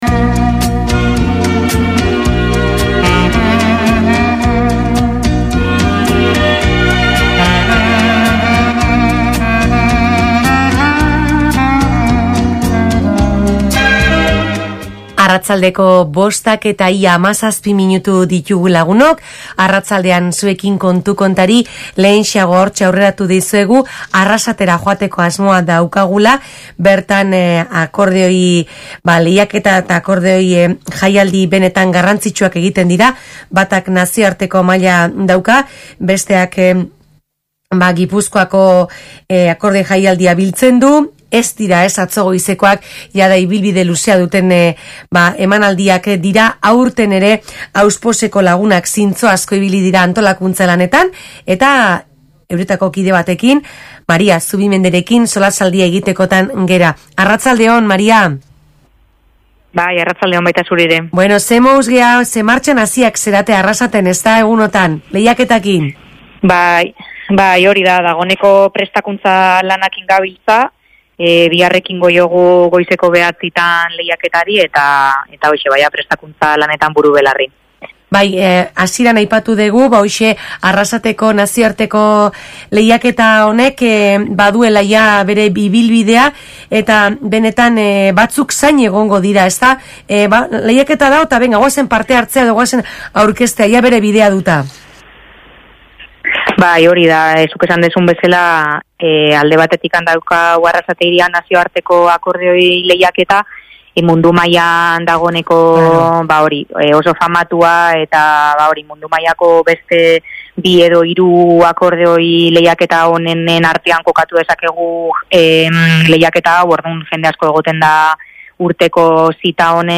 Alkarrizketa